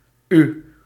Dutch pronunciation